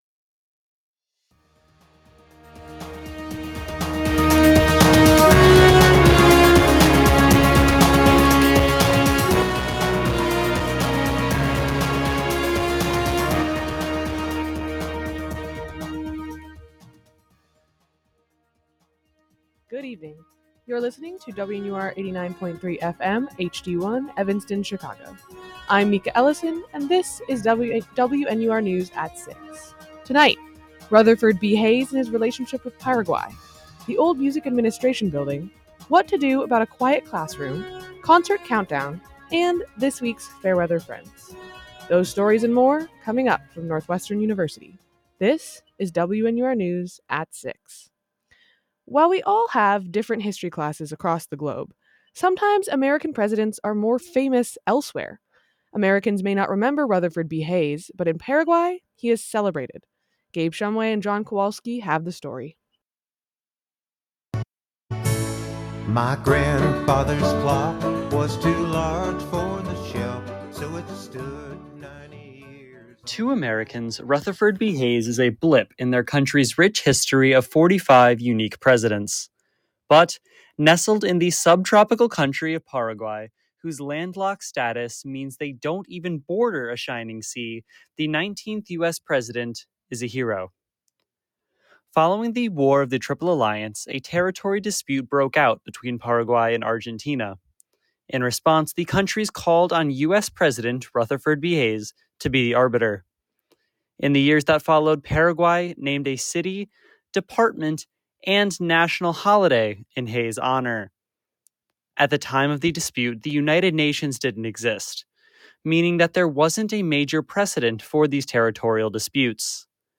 May 30, 2024: Rutherford B. Hayes and Paraguay, Music Administration building, quiet classrooms. WNUR News broadcasts live at 6 pm CST on Mondays, Wednesdays, and Fridays on WNUR 89.3 FM.